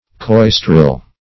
Search Result for " coistril" : The Collaborative International Dictionary of English v.0.48: Coistril \Cois"tril\, n. [Prob. from OF. coustillier groom or lad.